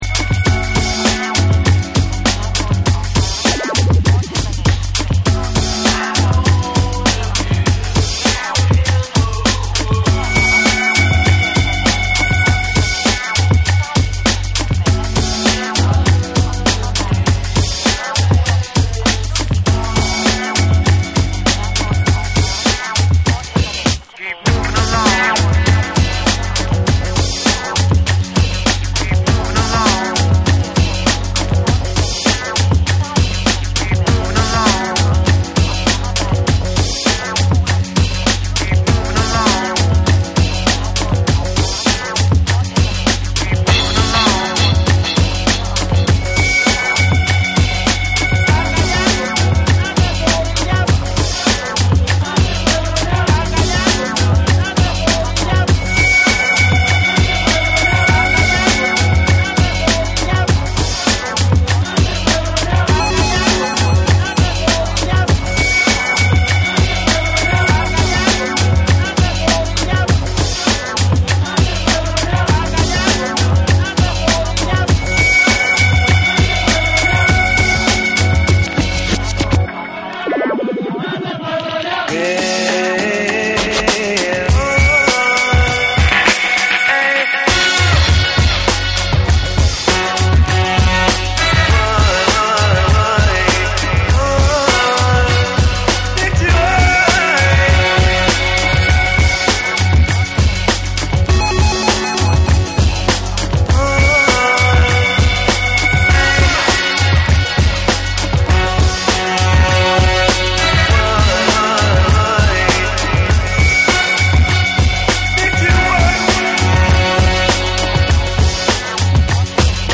深海を思わせる重厚なベースとダビーな音像が印象的なダウンテンポ／トリップホップ
ロウでスモーキーな質感の中にセンスが光る、隠れた好盤！